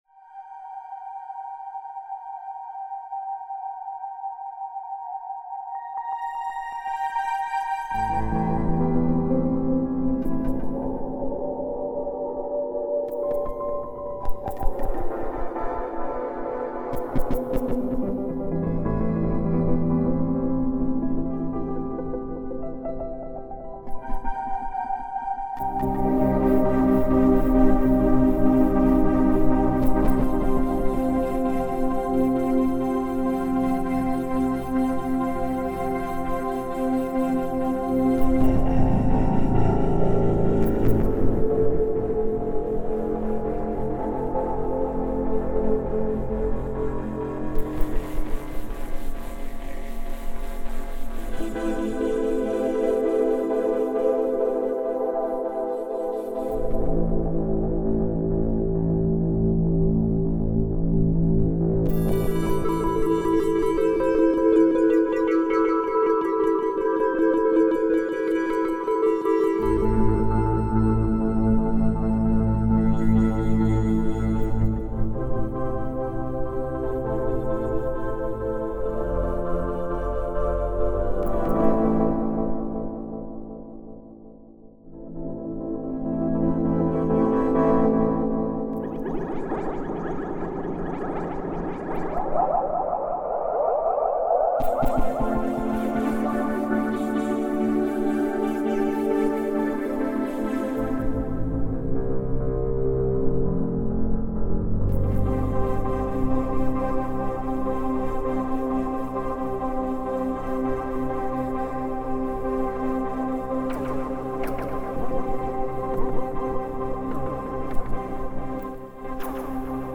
PYRATONE III 8192 voice synthesizer with MIDI2000, multi channel MIDI processor with ARPEGGIO and BEATMORPH VA-Synthesis @ 768 kHz, multi DSP engine and dynamic reverb @ 192/384 kHz Reverb Demo Design for Live Keyboard read more
pyraechoreverb.mp3